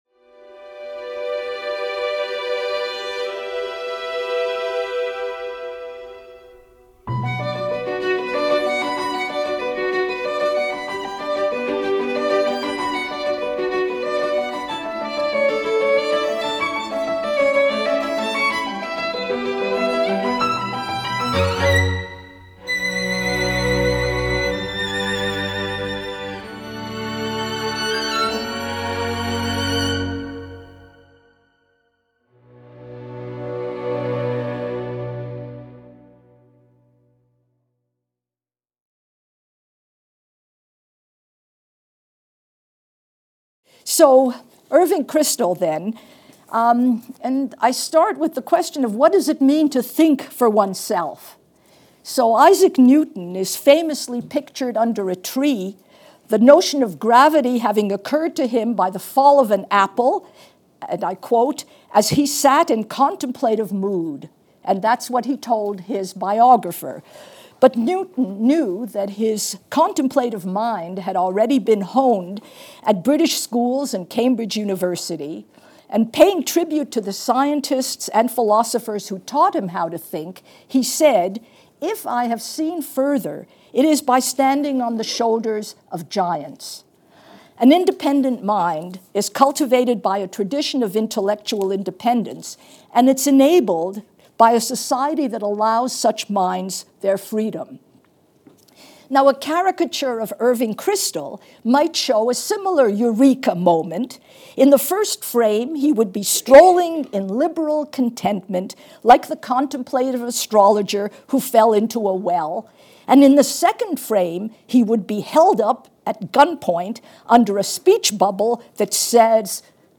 In this lecture, Professor Wisse considers the legacy of Irving Kristol.